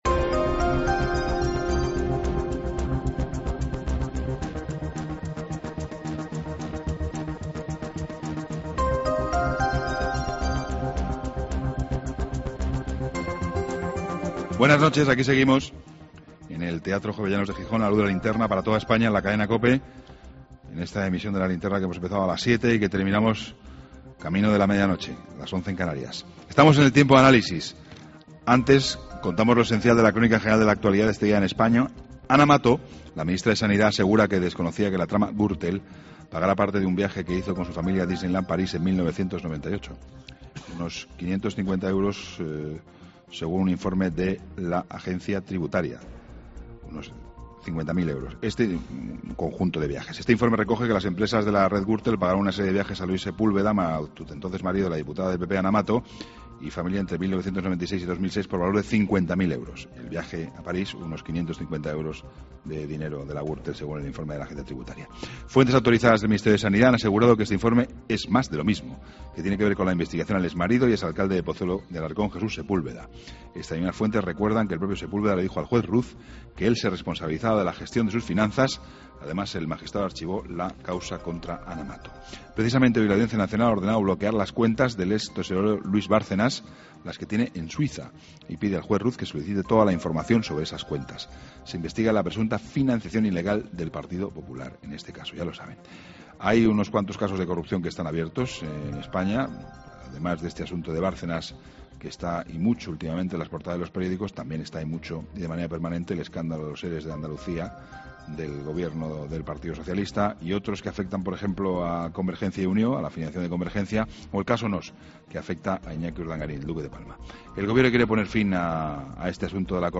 AUDIO: Toda la información con Juan Pablo Colmenarejo. Entrevista a José Luis Ayllón.